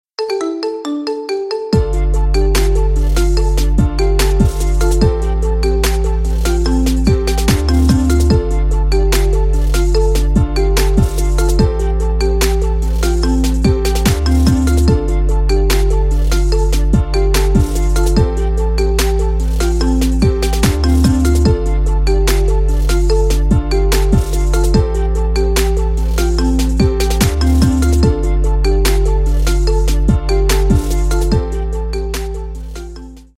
Рингтоны Без Слов